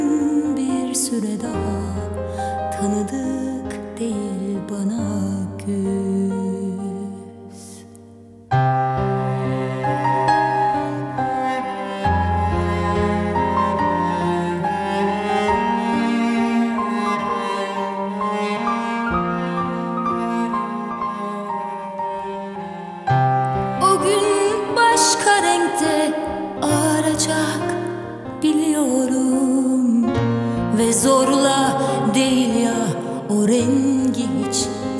Turkish Pop
Жанр: Поп музыка